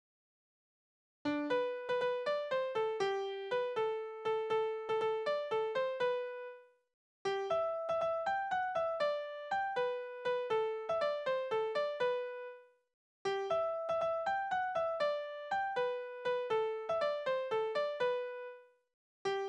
Naturlieder
Tonart: G-Dur
Taktart: 6/8
Tonumfang: Oktave, Quarte
Besetzung: vokal